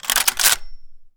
gun_rifle_cock_02.wav